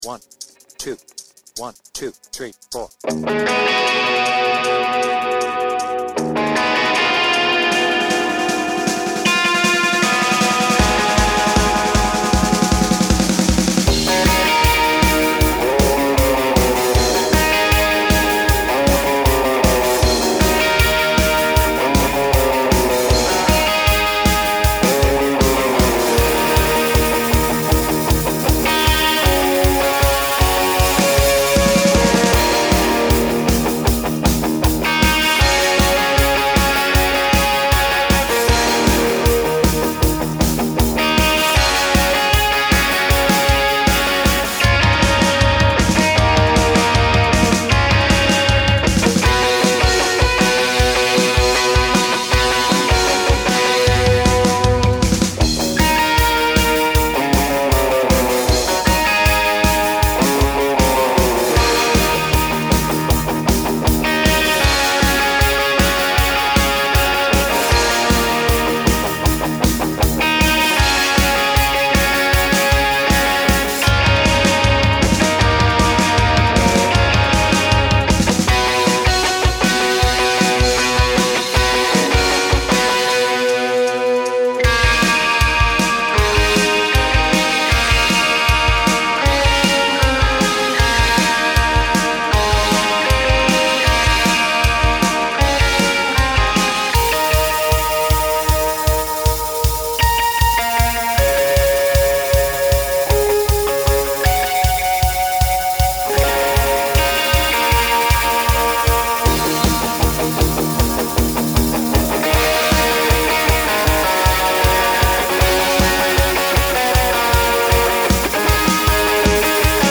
BPM : 156
Tuning : Eb
Without vocals
Based on the studio version